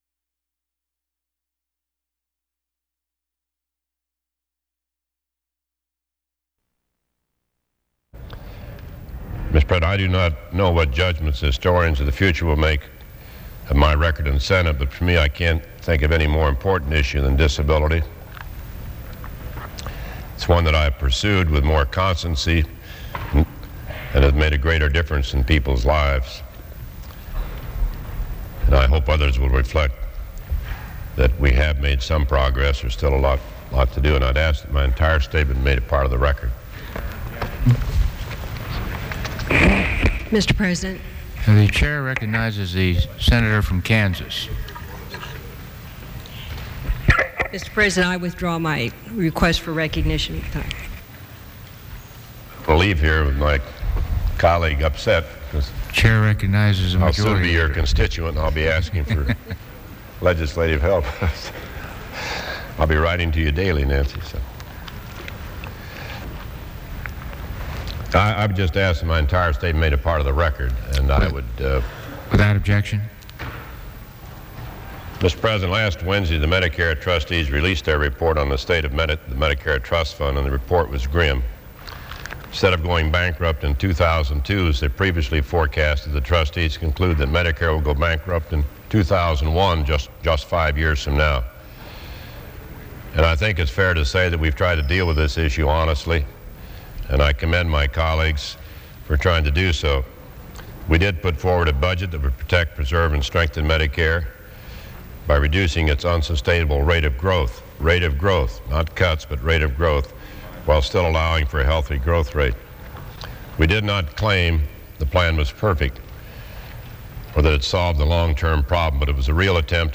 On his final day as a U.S. Senator, Sen. Bob Dole [R-KS] introduces a bill calling for a commission to reform Medicare